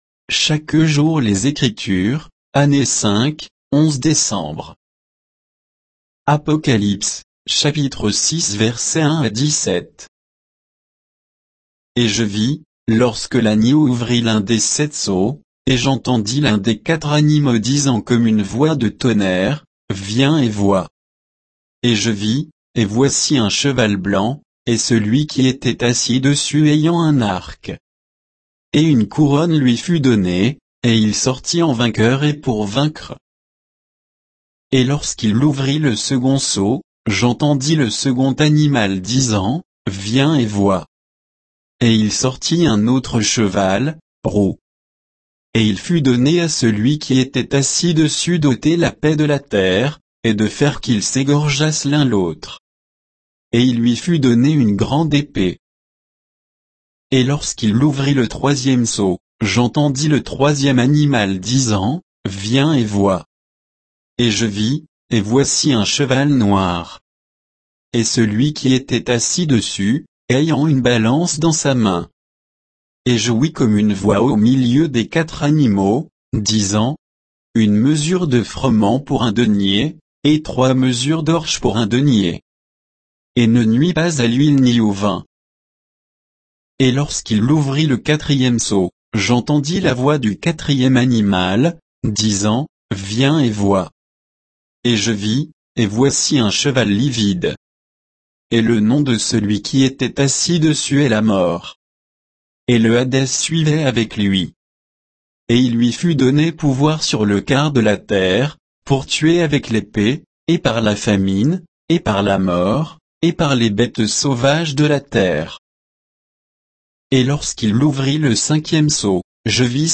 Méditation quoditienne de Chaque jour les Écritures sur Apocalypse 6, 1 à 17